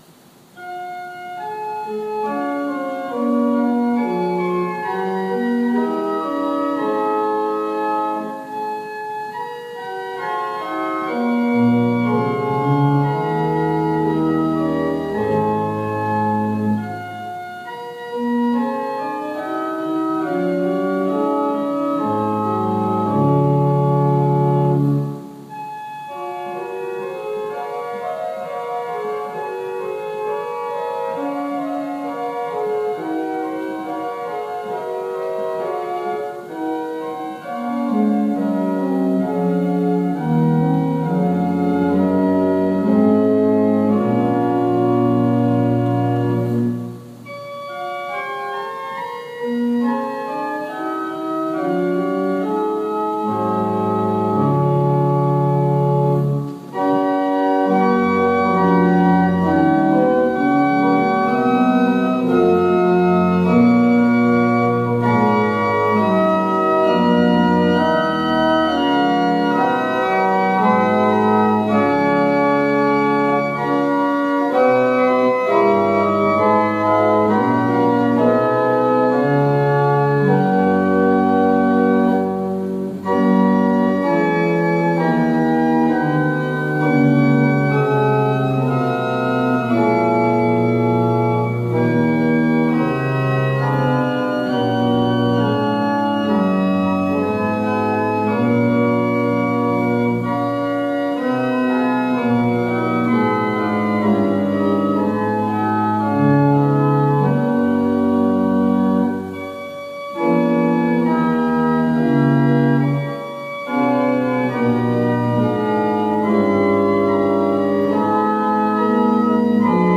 Worship Service December 13, 2020 | First Baptist Church, Malden, Massachusetts
Call to Worship / Invocation / Lord’s Prayer